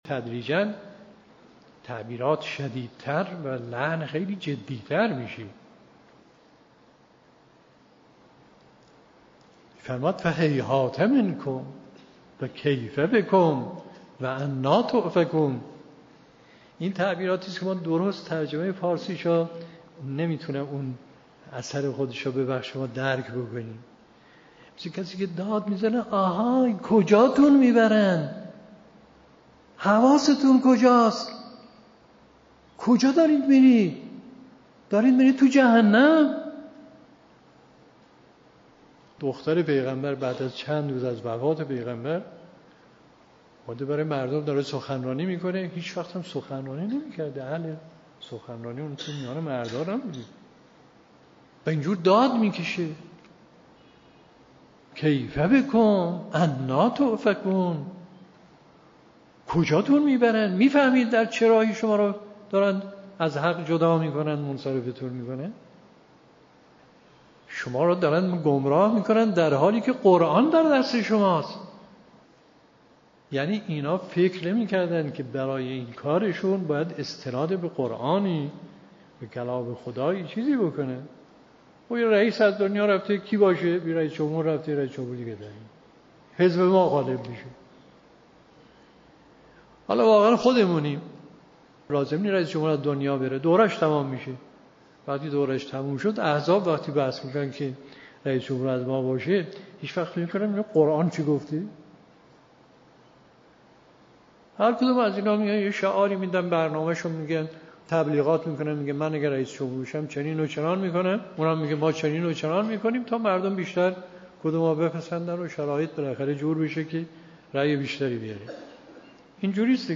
به گزارش خبرگزاری حوزه، به مناسبت ایام فاطمیه، گزیده‌ای از بیانات مرحوم آیت الله مصباح در رابطه با خطبه فدکیه حضرت زهرا سلام الله علیها تقدیم شما فرهیختگان می‌شود.